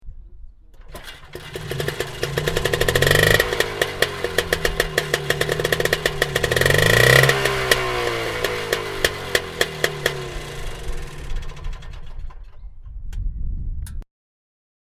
Motorsounds und Tonaufnahmen zu Messerschmitt Fahrzeugen (zufällige Auswahl)
Messerschmitt_KR_200_-_Startgeraeusch.mp3